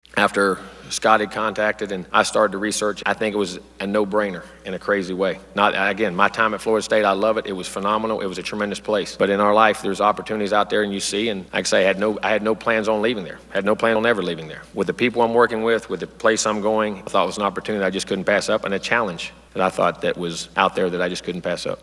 Fisher took to the podium to speak about his decision to take the job in College Station.